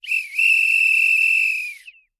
Звуки полицейского свистка
Свист дружинника